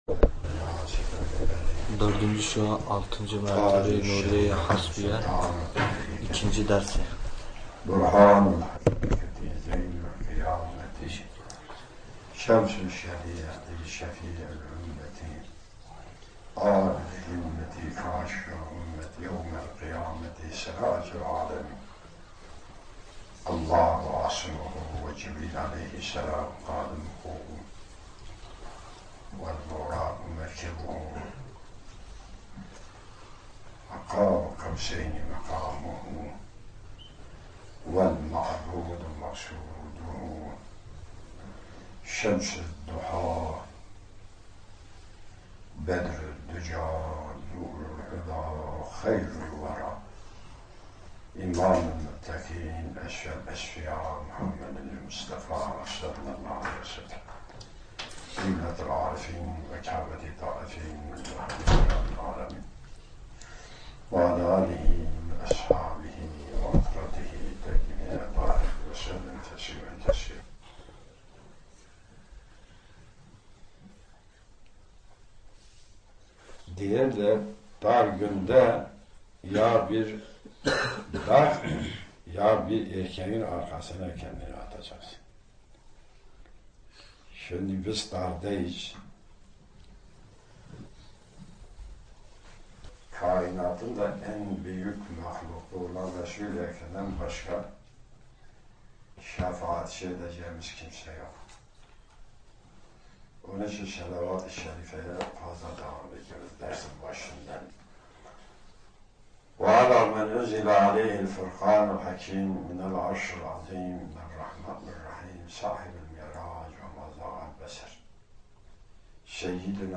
Hoca'mızdan 'Dördüncü Şuâ Altıncı Mertebe-i Nuriye-i Hasbiye' Dersi.